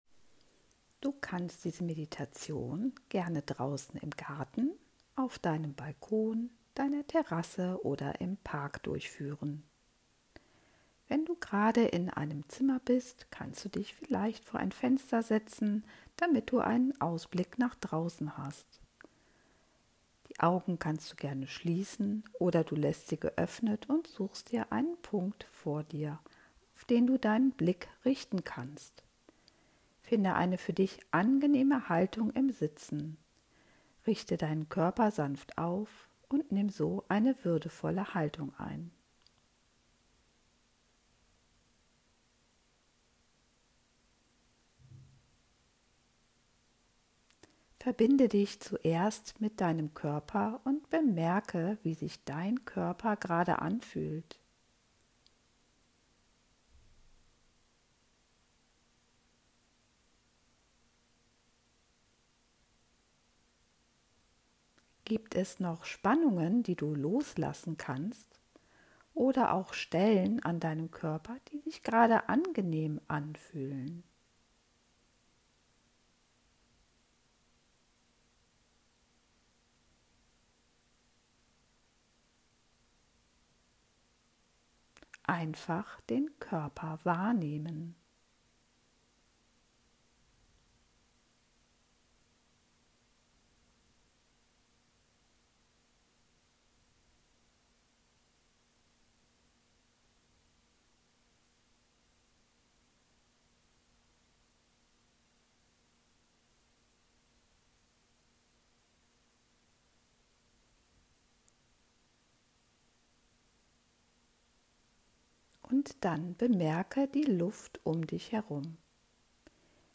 Meditation-fuer-die-Sommerzeit-.m4a